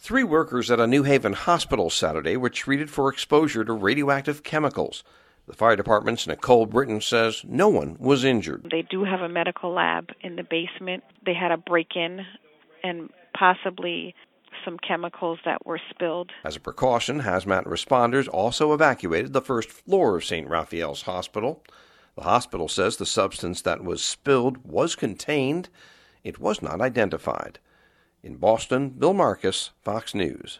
(BOSTON) JULY 16 – A RADIOACTIVE HAZMAT SITUATION IN NEW HAVEN, CONNECTICUT SATURDAY. FOX NEWS RADIO’S